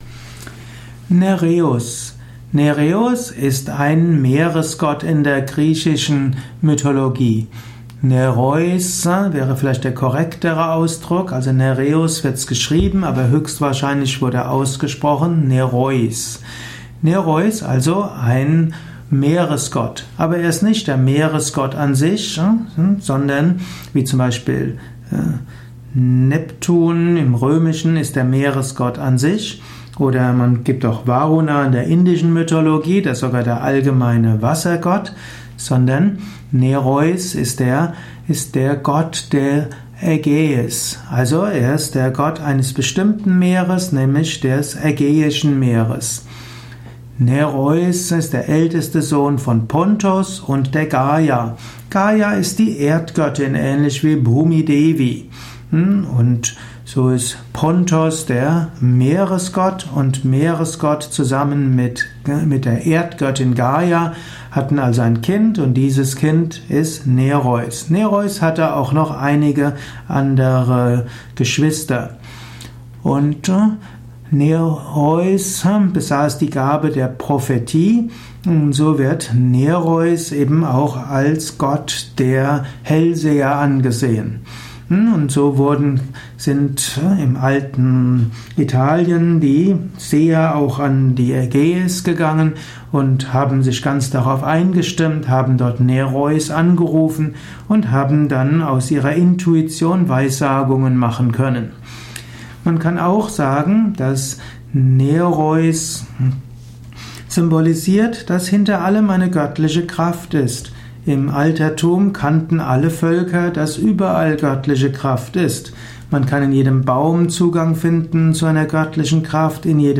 Ein Audio Vortrag über Nereus, einem griechischen Gott. Eruierung der Bedeutung von Nereus in der griechischen Mythologie, im griechischen Pantheon. Welche Bedeutung hat Gott Nereus für das spirituelle Leben, die Persönlichkeitsentwicklung, die spirituelle Praxis, die religiöse Philosophie? Dies ist die Tonspur eines Videos, zu finden im Yoga Wiki.